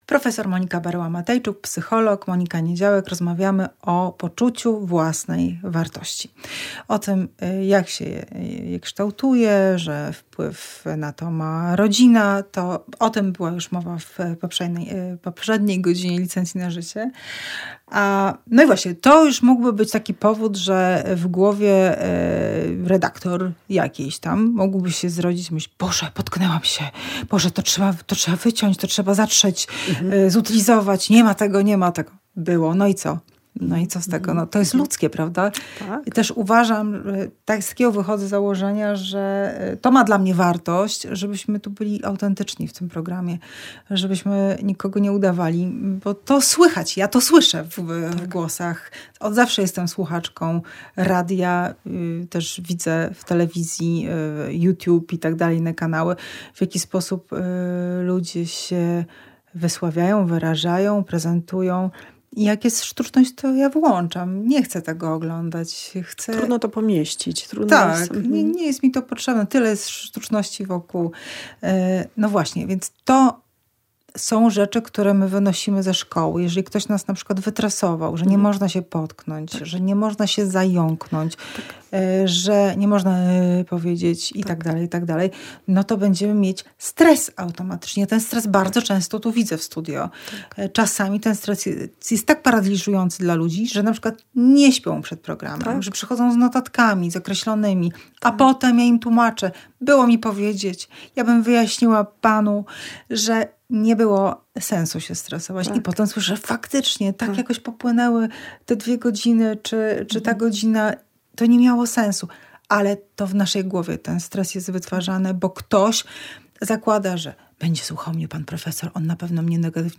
O poczuciu własnej wartości i samoocenie opowiada psycholog